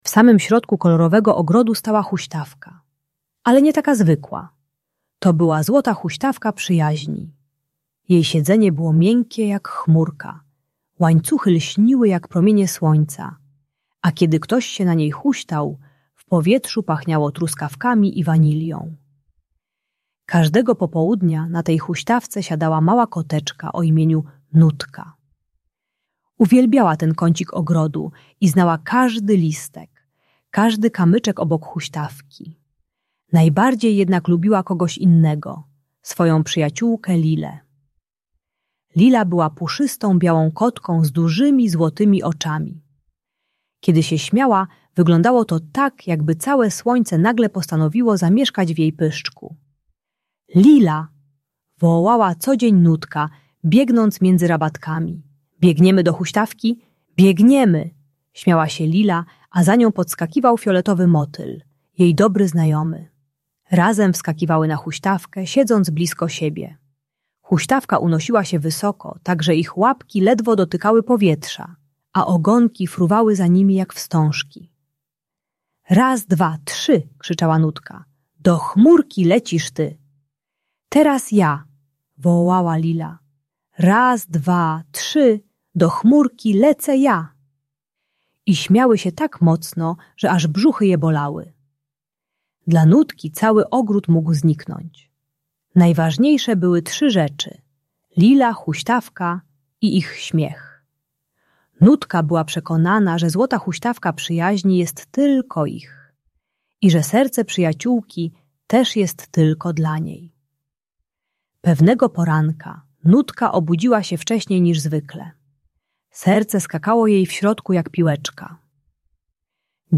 Złota Huśtawka Przyjaźni - Lęk wycofanie | Audiobajka